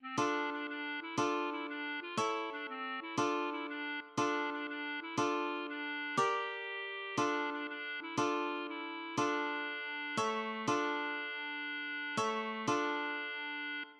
} myMusic = { << \chords { \germanChords \set chordChanges=##t \set Staff.midiInstrument="acoustic guitar (nylon)" s8 | d2.:m | d2.:m | a2. | d2.:m | d2.:m | d2.:m | g2.:m | d2.:m | d2.:m | d2.:m | a4. d4.:m~ | d2.:m | a4. d4.:m~ | d2:m s8 } \relative c' { \time 6/8 \partial 8 \tempo 4=180 \key d \minor \set Staff.midiInstrument="clarinet" c8 | d4 d8 d4 e8 | f4 e8 d4 f8 | e4 d8 c4 e8 | f4 e8 d4 r8 d4 d8 d4 e8 | f4. d4.